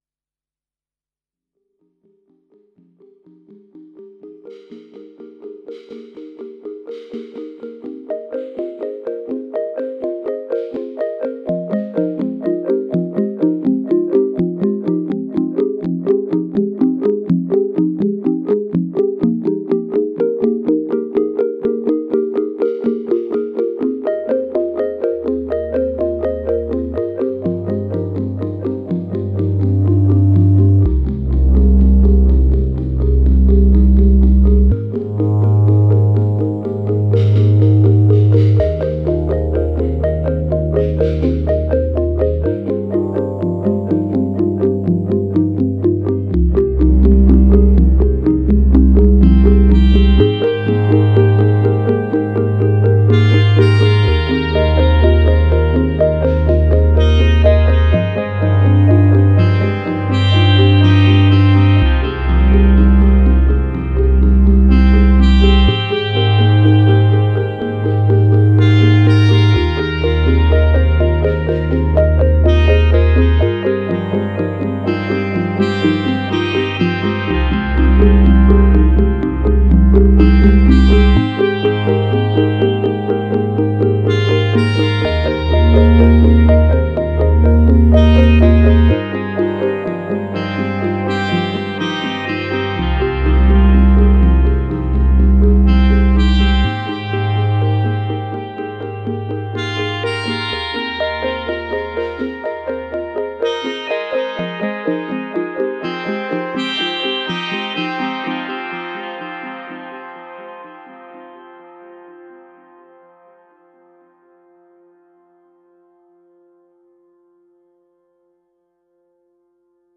But I fell in love with it making ambient…
Regarding the audio, arpeggio is one Sid track with delay set to delay time 47.
It is triggering a GND SIN track with delay set to delay time 63 which is triggering a reverb track which has a delay set to delay time 95.
The bass track is a Dynamic FM machine with low envelope settings to creates this pulsating bass.
Lead is DPRO WAVE going into CXM 1978
Granted the MNM reverb is more suited for percussive elements rather than synth lines, it stills sits decently in the mix.